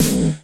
Snare - Roland TR 47